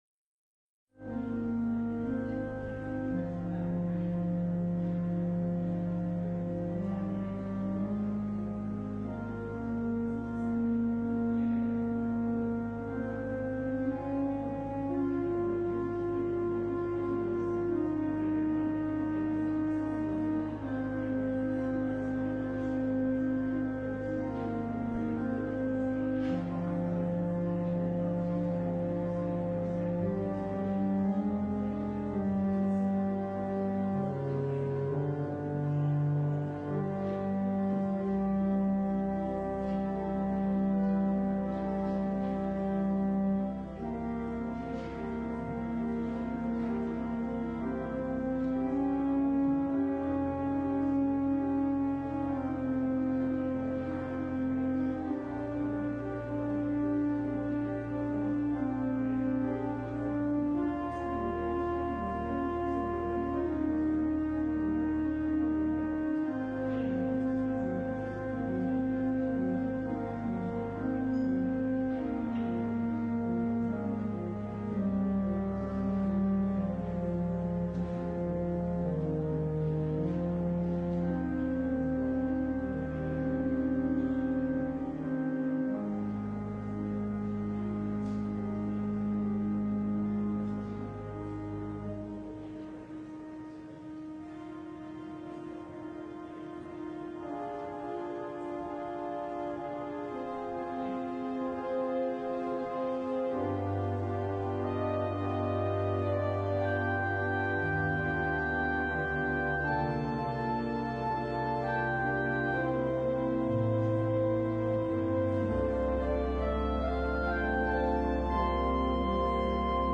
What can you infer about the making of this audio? LIVE Morning Worship Service - The Prophets and the Kings: Naaman